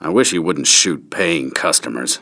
File File history File usage BaronsHed_Bartender_Paying_Customers.ogg (file size: 33 KB, MIME type: application/ogg ) Summary File:BaronsHed Bartender Paying Customers.ogg Information Description Unidentified Barons Hed bartender doesn't realize that peace was never an option. Source Star Wars: Jedi Knight: Dark Forces II Licensing This is an Ogg Vorbis sound sample.
BaronsHed_Bartender_Paying_Customers.ogg